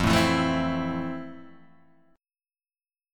E#maj7 chord